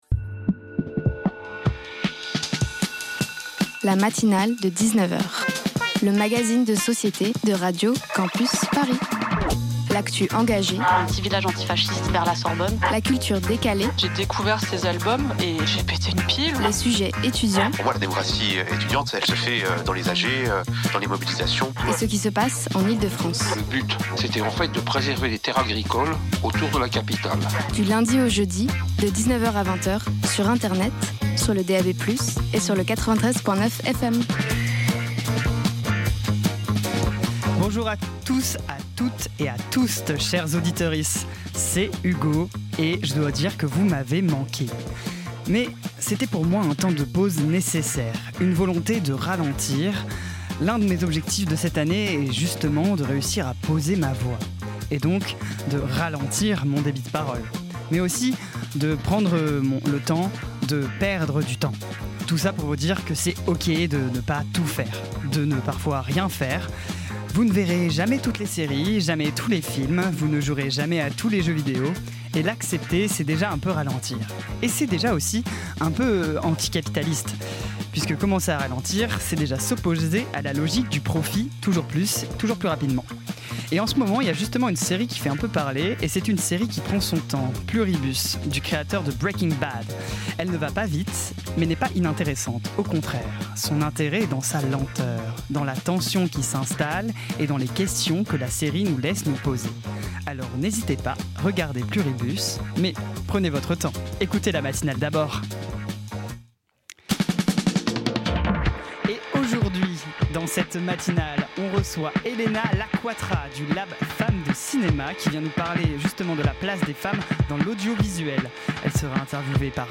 Type Magazine Société Culture